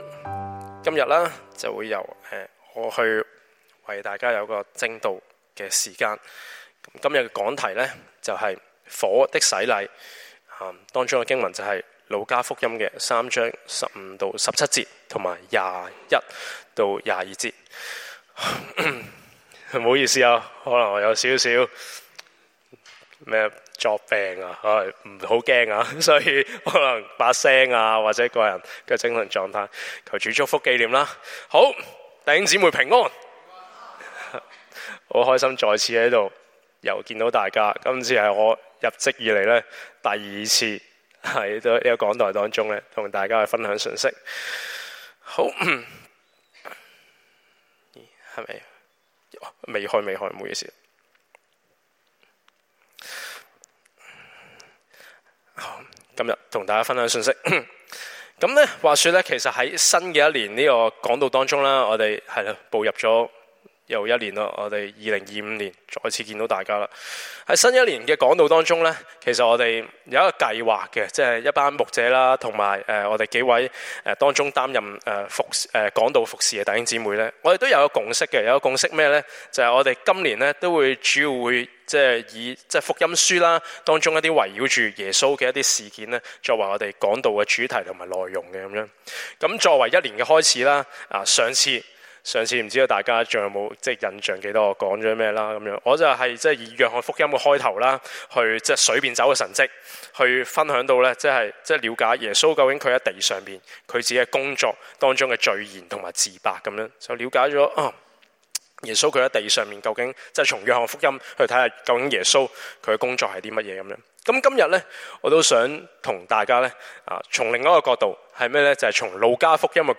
Sermon – 第 2 頁 – 澳亞基督教會 Austral-Asian Christian Church